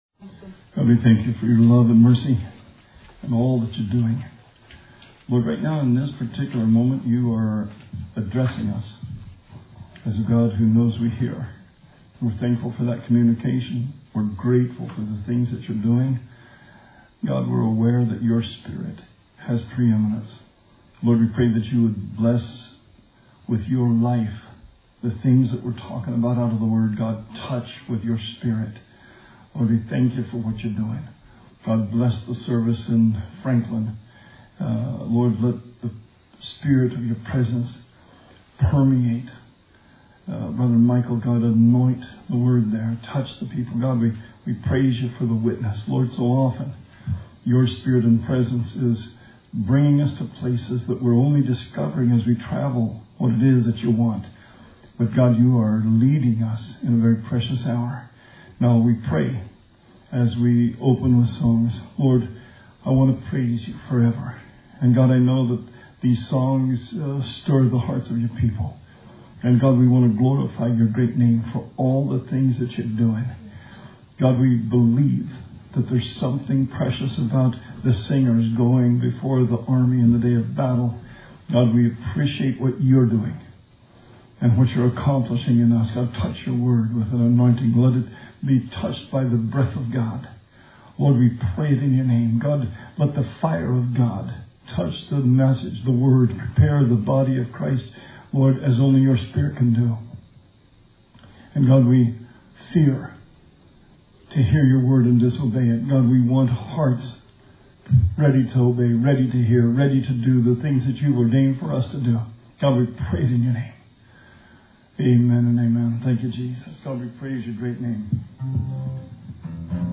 Bible Study 4/1/20